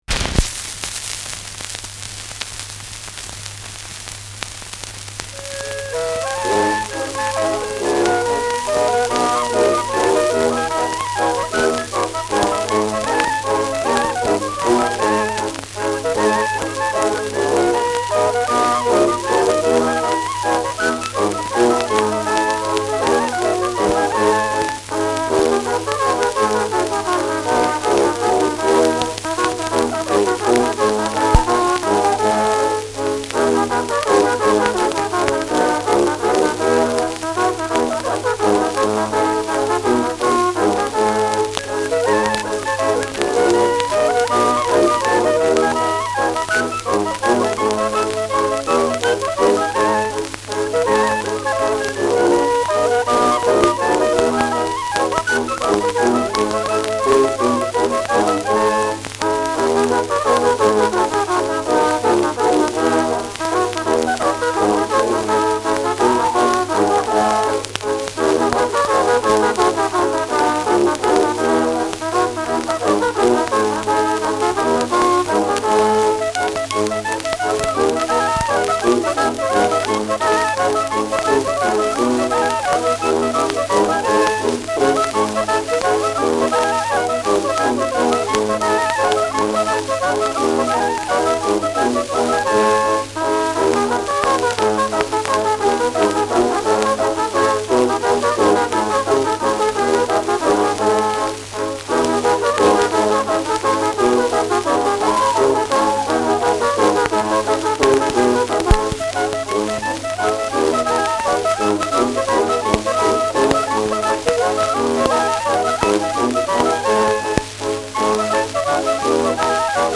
Schellackplatte
Abgespielt : Gelegentlich starkes Knacken : Tonarm springt häufig